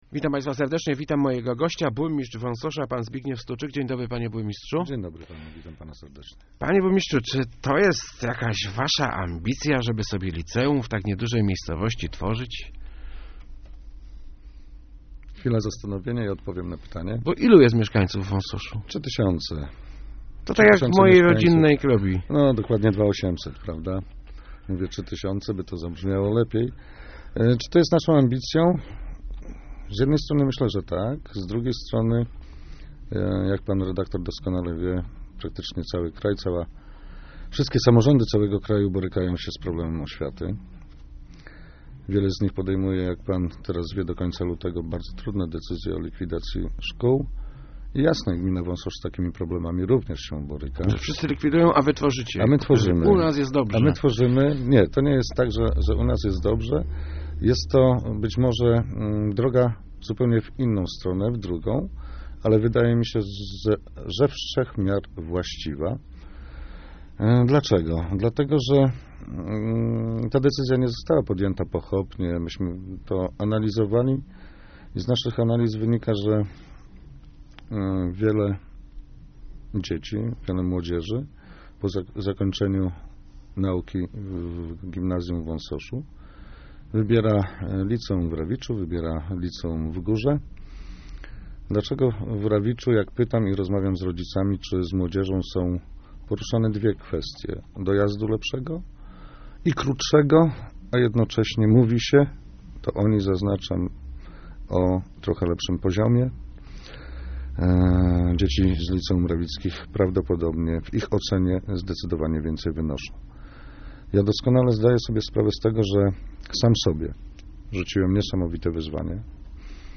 W naszym liceum nauczyciele wcale nie będą mieli gorzej - zapewniał w Rozmowach Elki burmistrz Wąsosza Zbigniew Stuczyk. Chce on utworzyć szkołę o profilu mudrowym, strażackim. Będzie ona prowadzona przez stowarzyszenie, a więc nie będzie w nim obowiązywać Karta Nauczyciela.